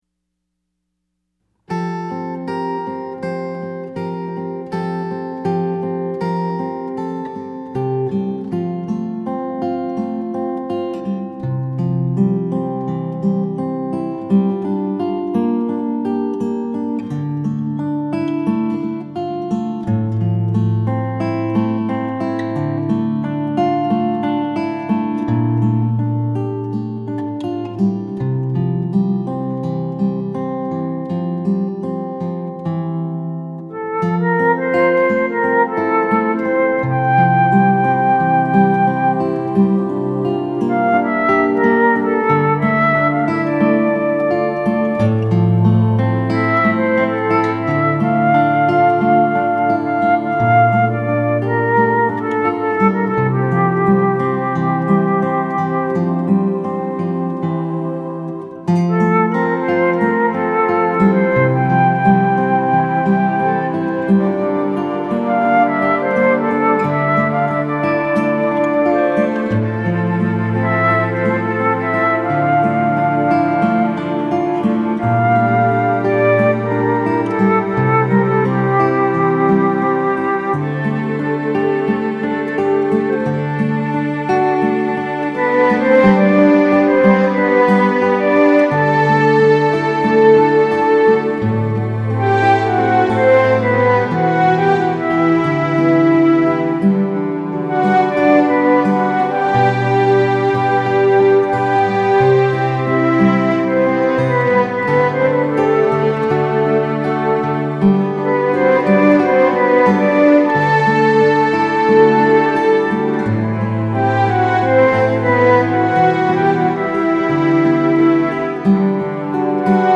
Acoustic
through-my-music-1-instrumental.mp3